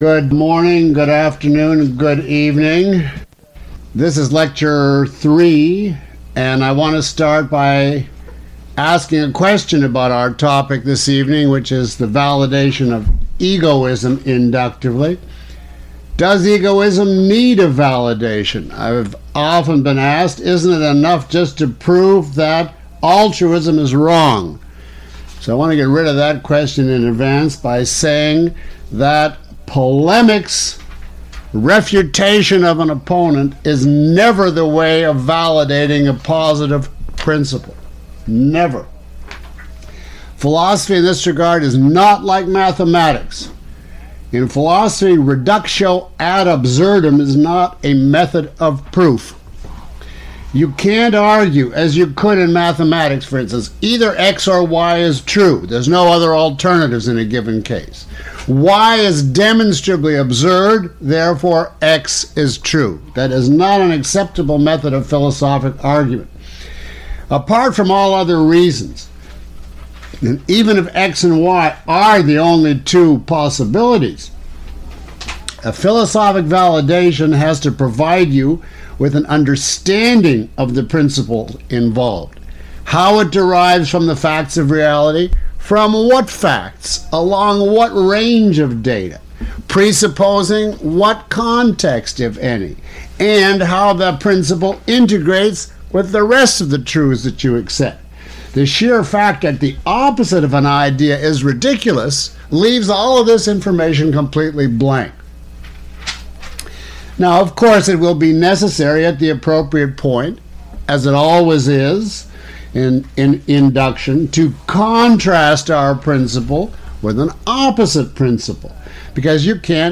Lecture (MP3) Full Course (ZIP) Lecture Two Course Home Lecture Four Questions about this audio?